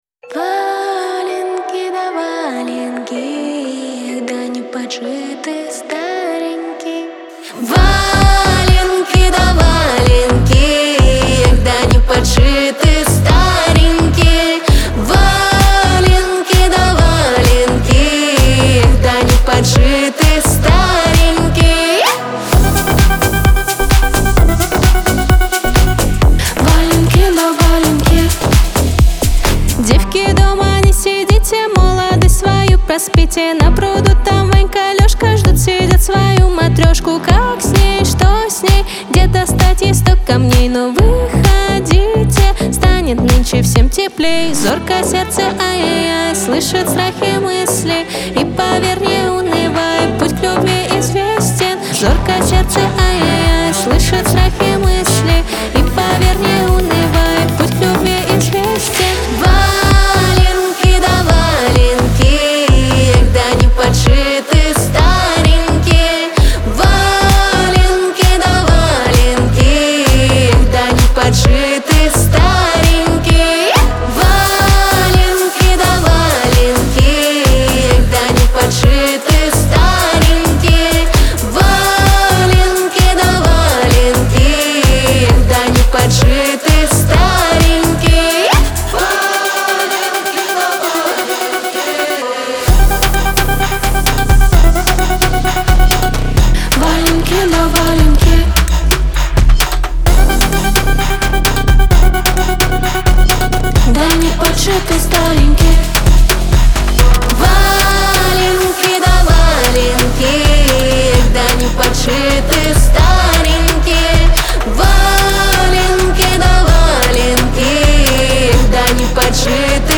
диско
Кавер-версия